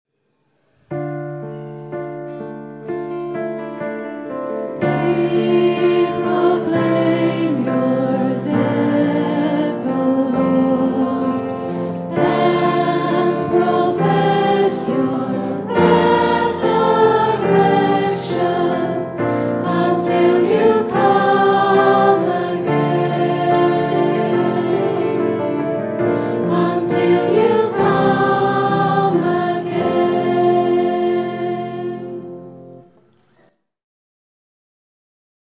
Recorded on a Zoom H4 digital stereo recorder at 10am Mass, Sunday 4th September 2011.